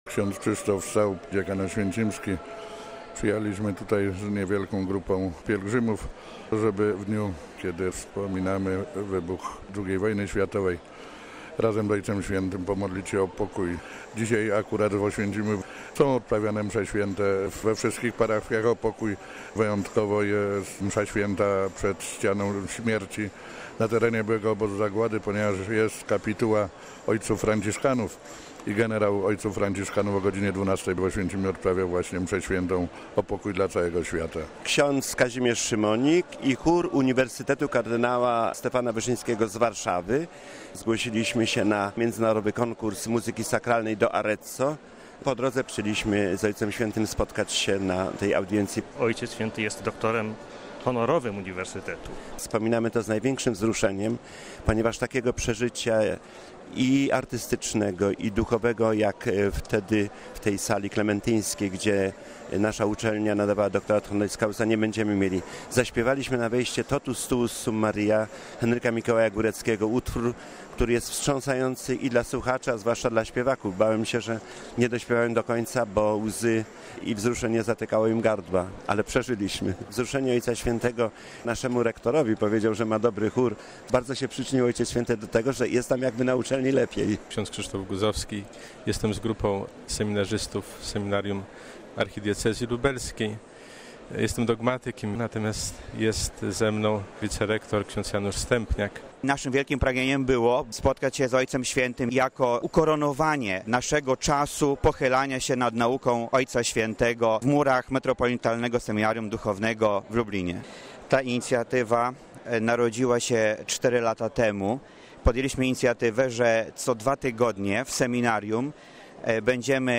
Wśród pół tysiąca Polaków w Auli Pawła VI byli krakowscy biskupi nominaci Jan Zając i Józef Guzdek. Z pielgrzymami rozmawiał nasz reporter: